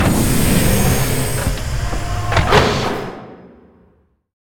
taxiopen.ogg